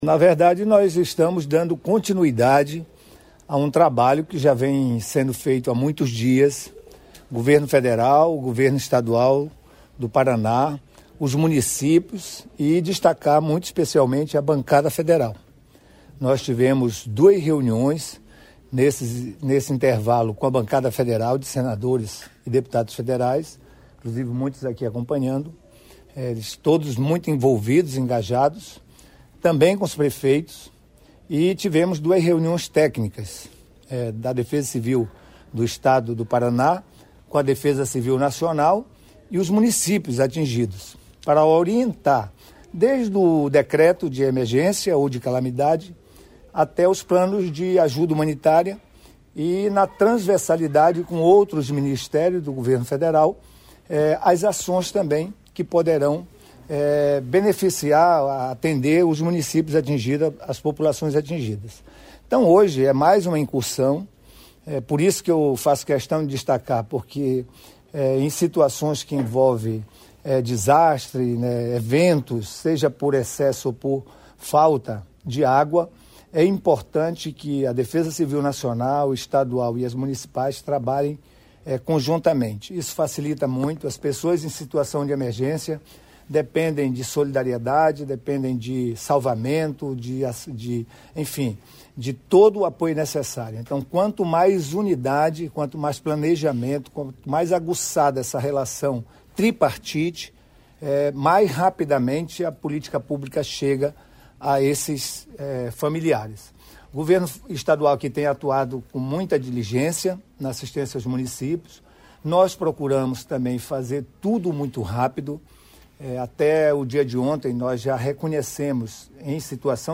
Sonora do Ministro da Integração, Waldez Góes, sobre a visita à União da Vitória para anúncio de recursos aos afetados pelas chuvas